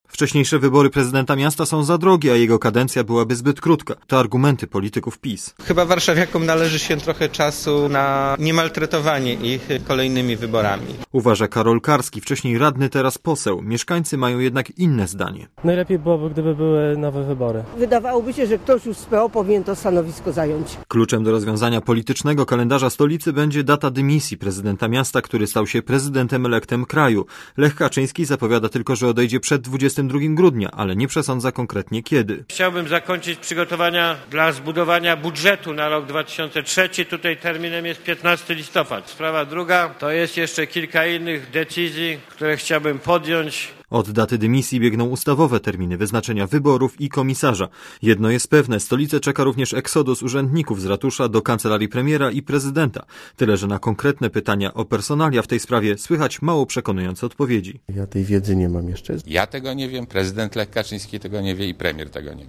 reportera Radia ZET*